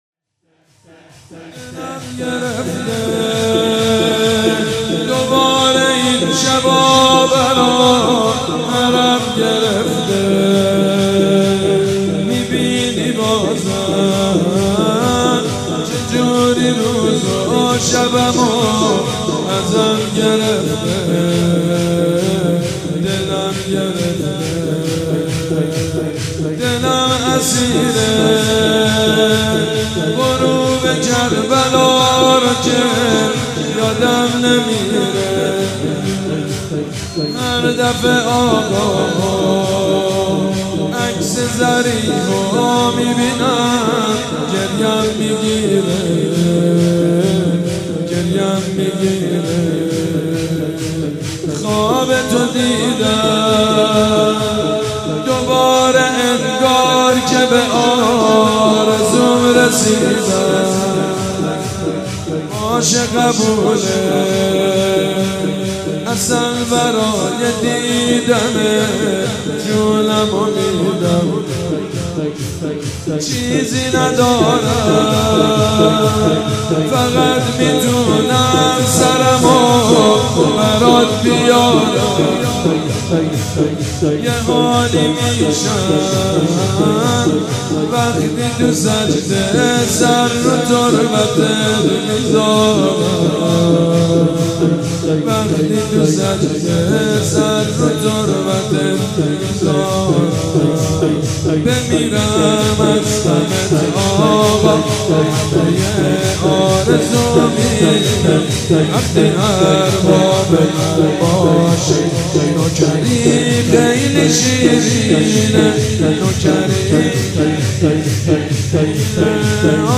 مداحی شنیدنی و سوزناک
در شام غریبان محرم 93
شور